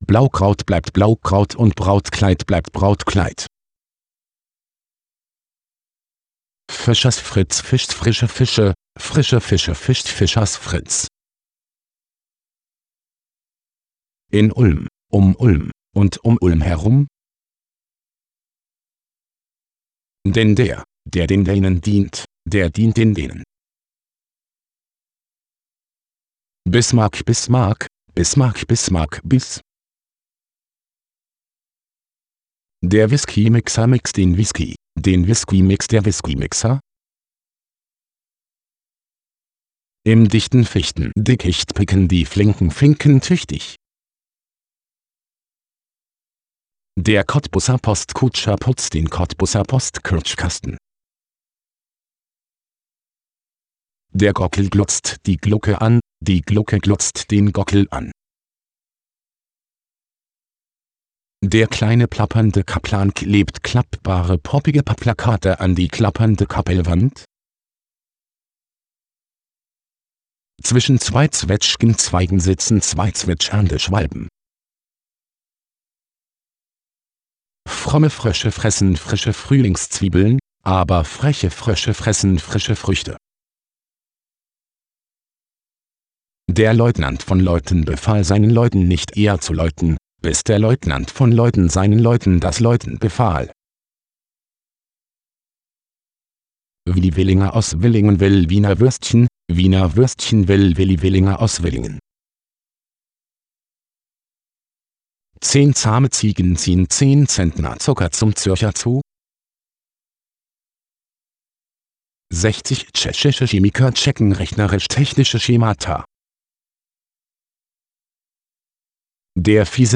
Hier im Folgenden also eine Sammlung der beliebtesten Zungenbrecher. Wer diese problemlos mehrere Mal hintereinander richtig aussprechen kann, muss eine Maschine sein.